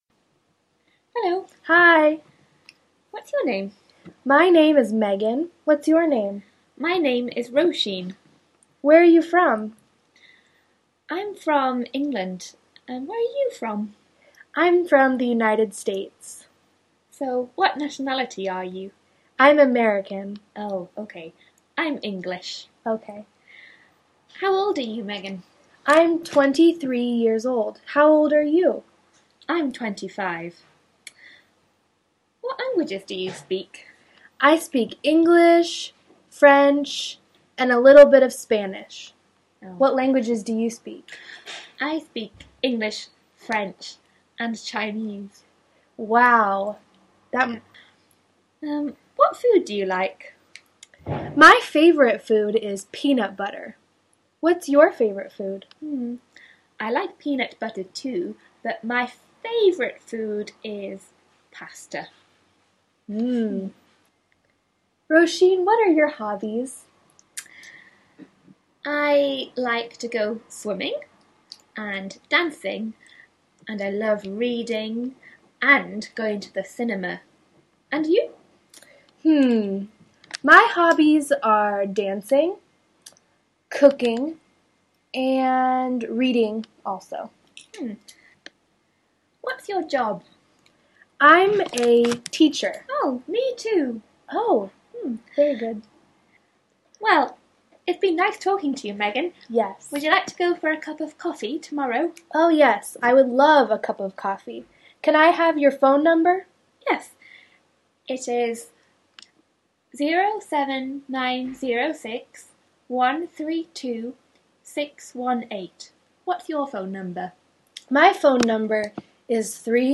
dialogue de présentation